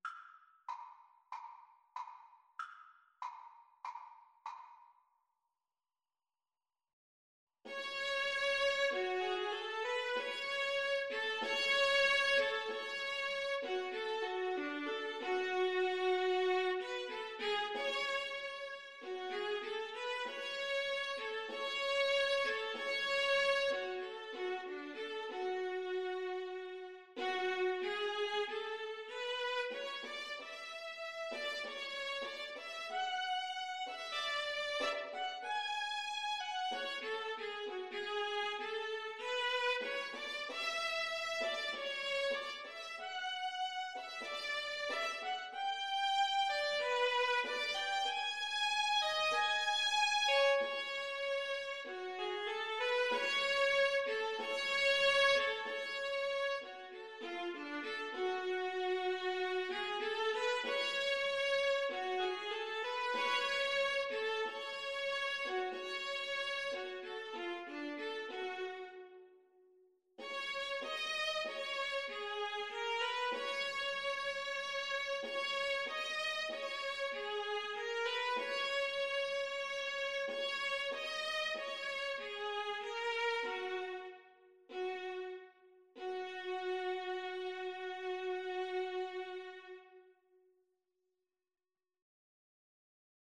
F# minor (Sounding Pitch) (View more F# minor Music for Viola Duet )
Andante = 95
Classical (View more Classical Viola Duet Music)